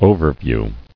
[o·ver·view]